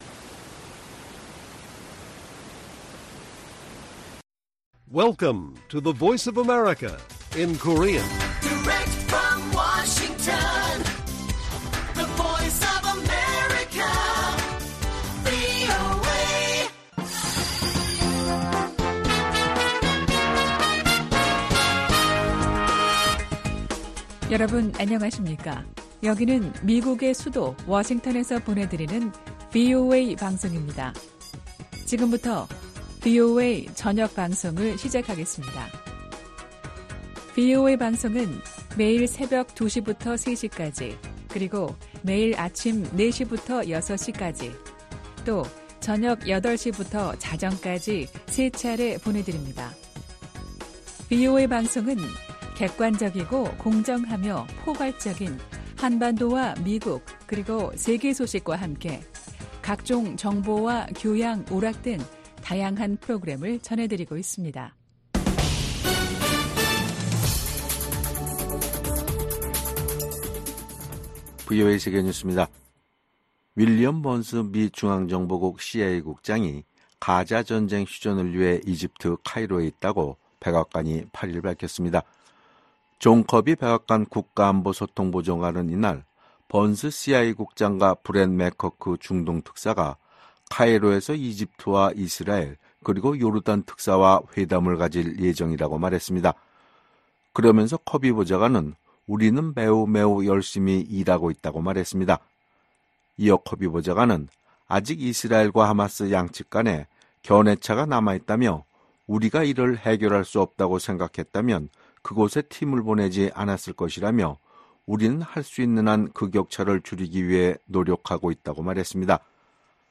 VOA 한국어 간판 뉴스 프로그램 '뉴스 투데이', 2024년 7월 9일 1부 방송입니다. 오늘 9일부터 11일까지 이곳 워싱턴에서는 32개국 지도자들이 참석하는 나토 정상회의가 열립니다.